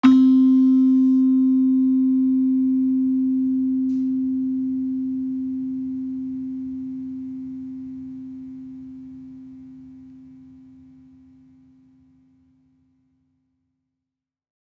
Gamelan Sound Bank